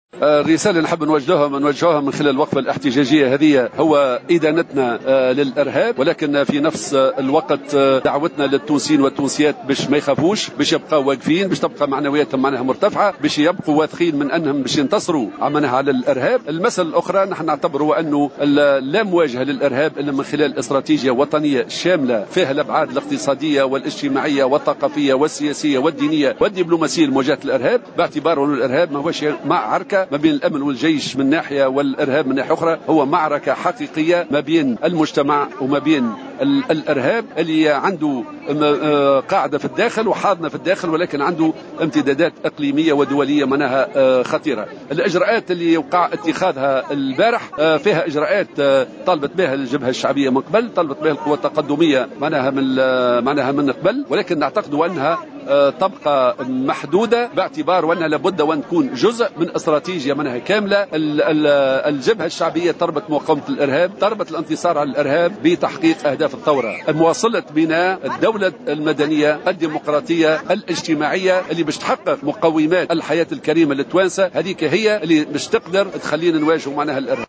خلال مسيرة جابت شارع الحبيب بورقيبة في العاصمة مساء اليوم السبت 27 جوان للتنديد بالارهاب